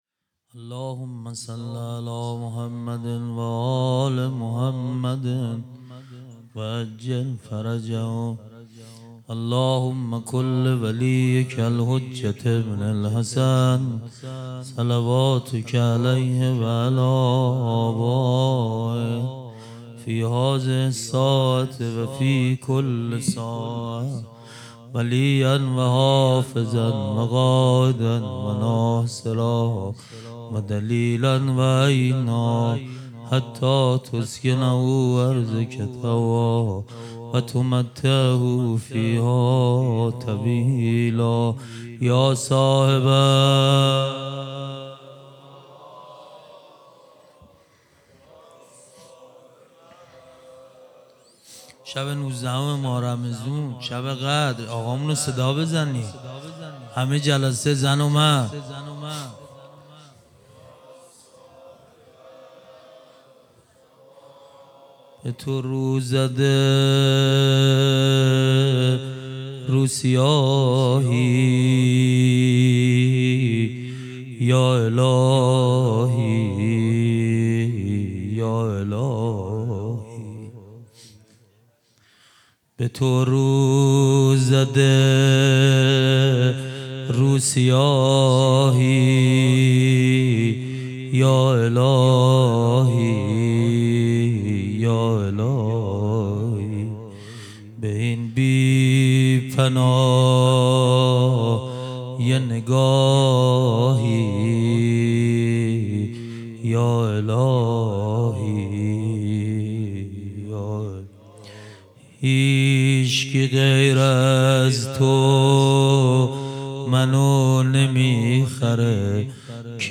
خیمه گاه - هیئت محبان الحسین علیه السلام مسگرآباد - روضه